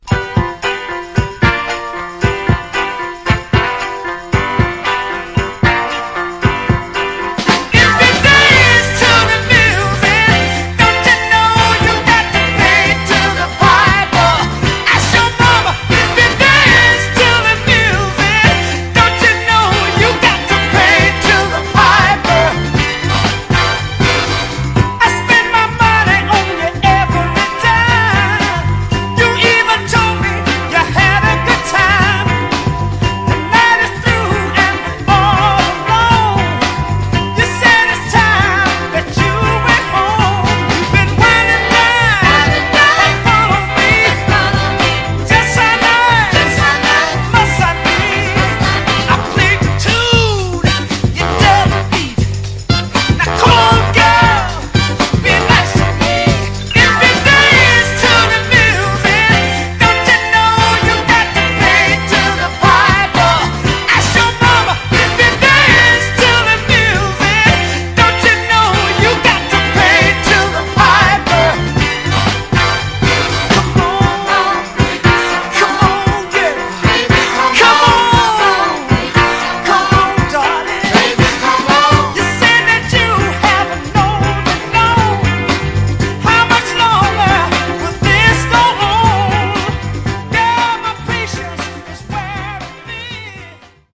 タフなヴァイナル・プレス。
※試聴音源は実際にお送りする商品から録音したものです※